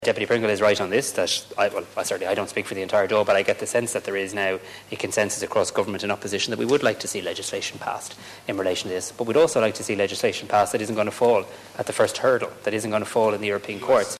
The Dáil has heard calls from a Donegal a Deputy for the Government to fast track the Occupied Territories Bill and pass it before the election is called.
Taoiseach Simon Harris has indicated that won’t happen before he dissolves the Dáil: